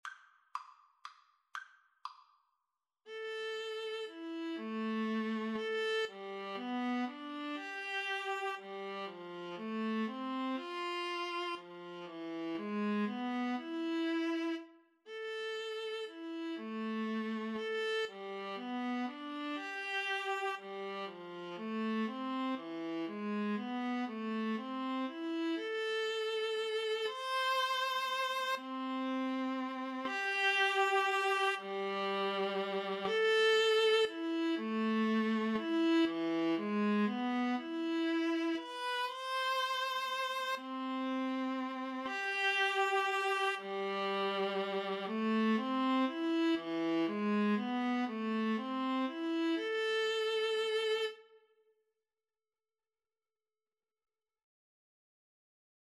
Play (or use space bar on your keyboard) Pause Music Playalong - Player 1 Accompaniment transpose reset tempo print settings full screen
A minor (Sounding Pitch) (View more A minor Music for Violin-Viola Duet )
3/4 (View more 3/4 Music)